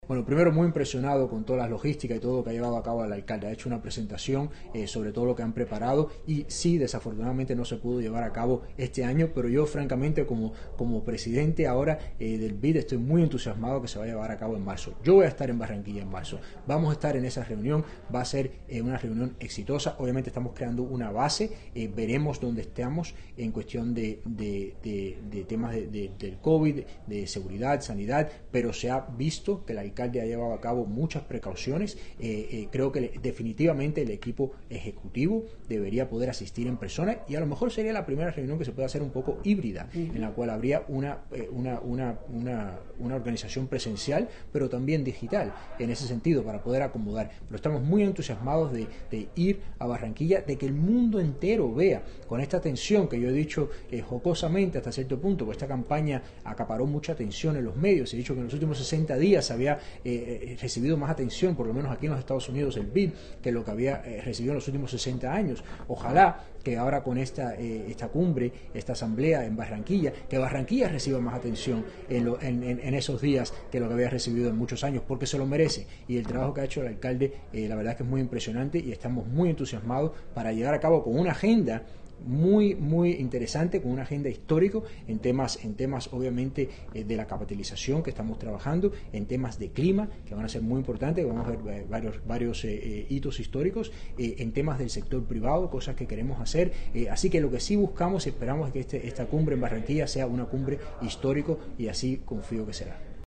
En reunión con el alcalde Jaime Pumarejo y la misión distrital que cumple agenda de trabajo en Washington, el nuevo presidente del Banco Interamericano de Desarrollo (BID), Mauricio Claver-Carone, “se puso la camiseta”, ratificó a Barranquilla como sede de la Asamblea del BID 2021 y respaldó los avances en los preparativos de la ciudad de cara al evento que será la punta de lanza para la reactivación económica y el bienestar de sus habitantes.
5-AUDIO-FULL-MAURICIO-CLAVER-CARONE-REUNION-BID-WASHINGTON.mp3